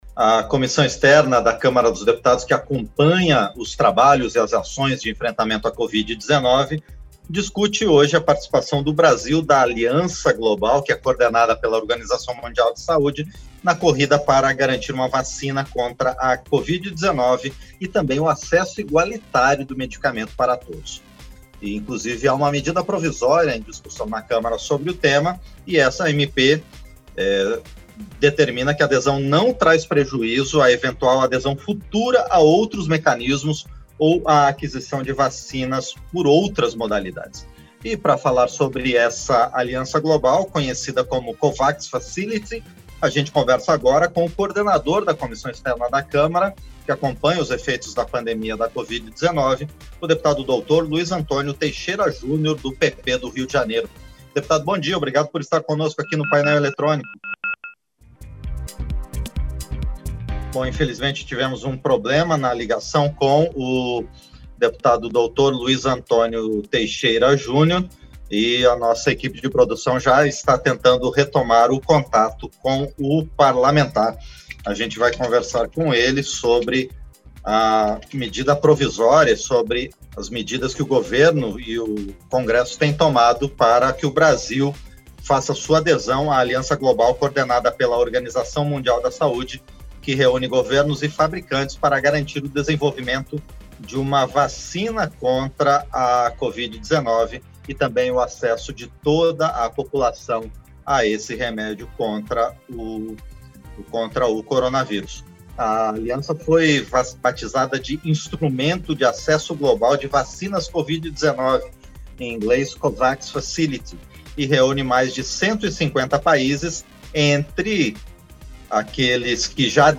Entrevista - Dep. Dr. Luiz Antônio Teixeira Jr. (PP-RJ)